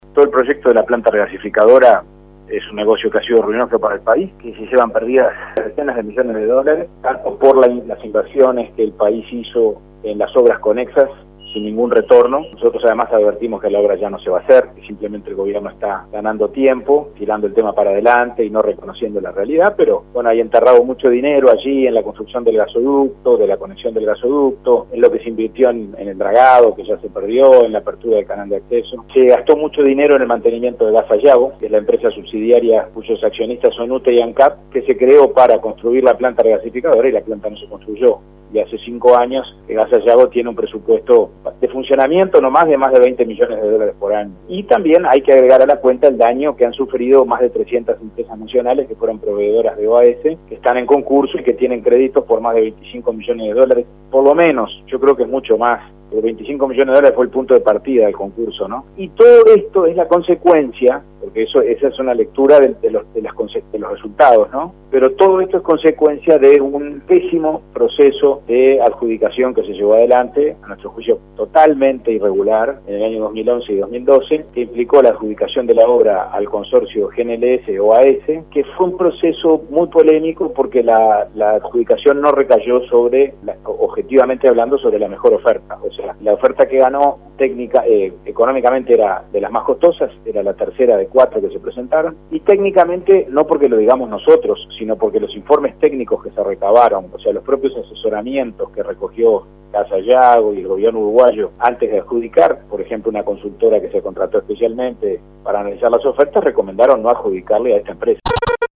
El diputado Pablo Abdala, miembro denunciante en estas causas, explicó a Rompkbzas cuáles fueron los motivos que llevó a la bancada nacionalista a denunciar estos hechos.